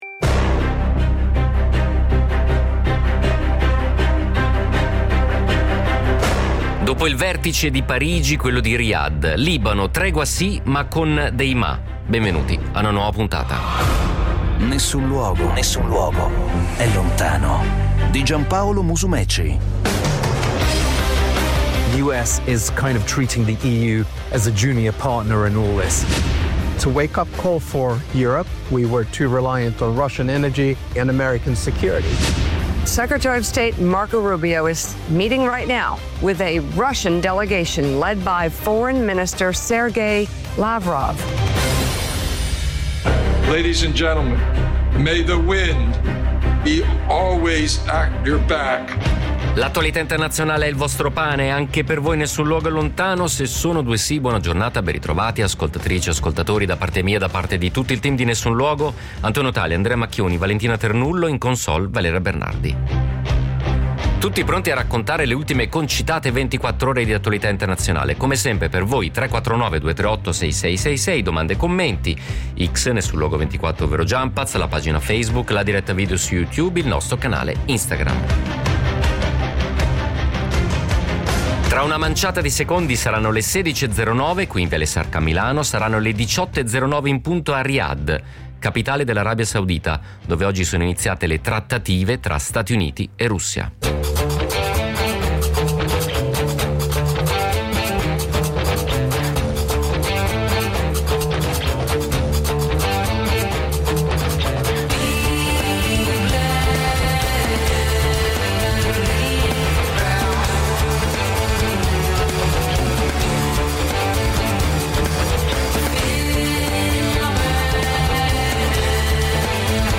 accende il microfono e accoglie reporter, fotografi, analisti, i più autorevoli a livello internazionale.
… continue reading 772 episode # News Talk # Notizie # Radio 24